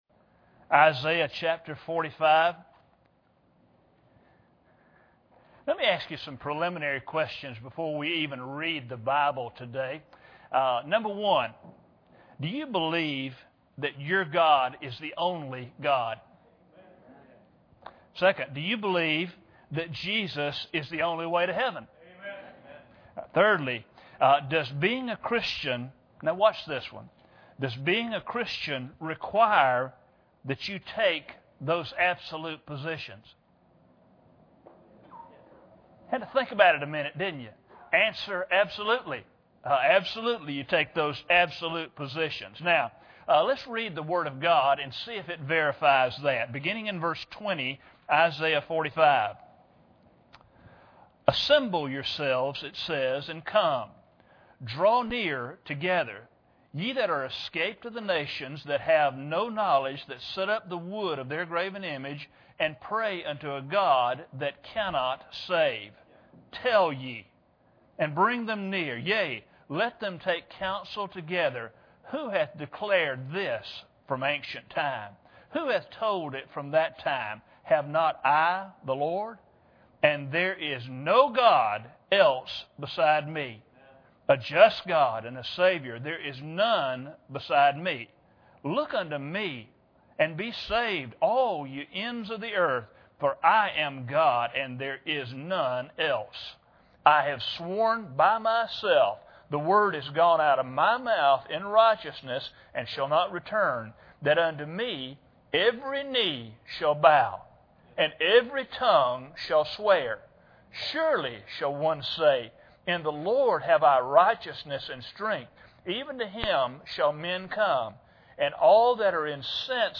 Isaiah 45:20-25 Service Type: Sunday Morning Bible Text